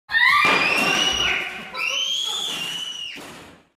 Play menina grita, Download and Share now on SoundBoardGuy!
PLAY menina grita
menina-grita.mp3